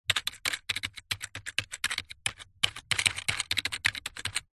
Человек набирает текст на клавиатуре компьютера